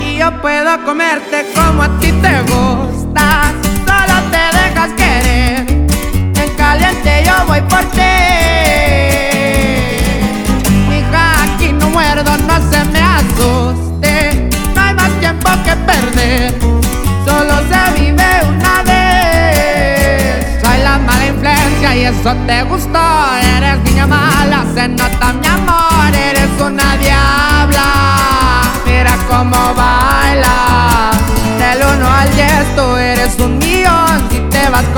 # Música Mexicana